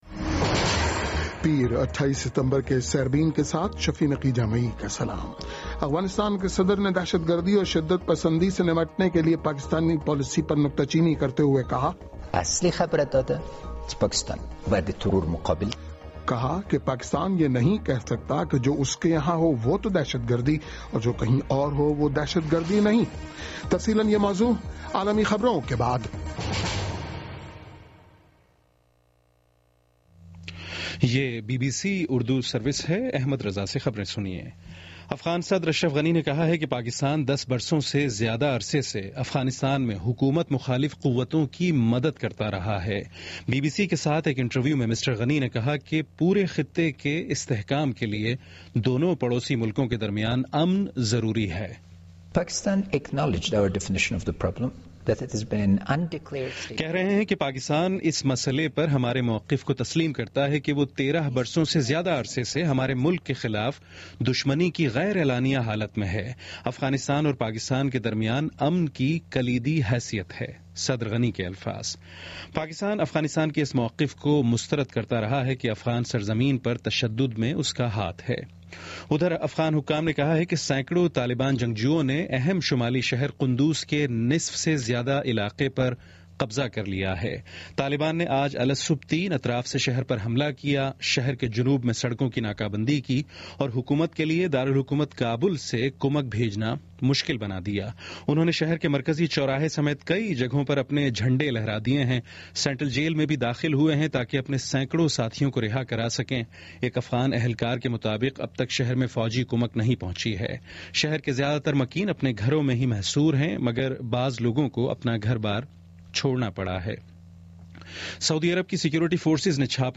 پیر 28 ستمبر کا سیربین ریڈیو پروگرام